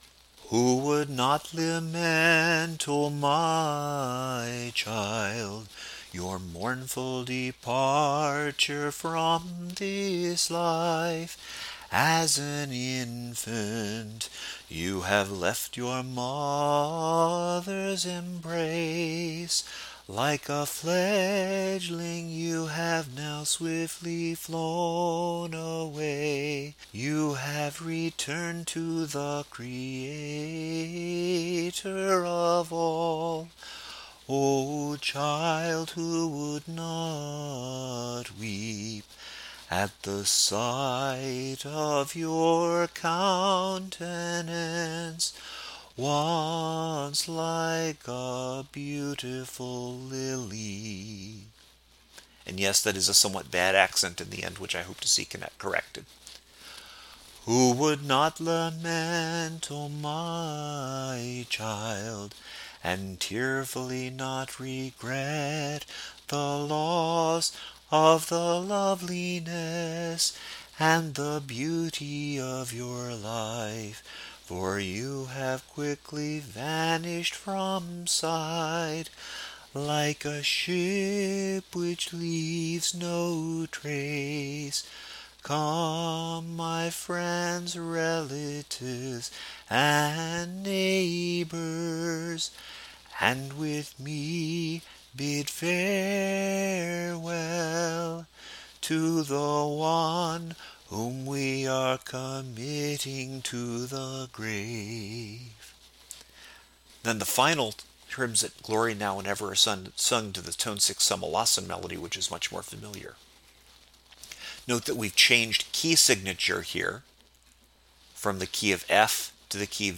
After the Ambon Prayer of the Divine Liturgy, or immediately after the Gospel (and homily) if there is no Divine Liturgy, the Hymns of Farewell for a Departed Child are sung, using a special melody from the feast of the Dormition called O preslavnaho čudese.
Listen to these hymns - listen to the O preslavnaho čudese melody in Slavonic.)
Funeral_for_a_Child_Hymns_of_Farewell.mp3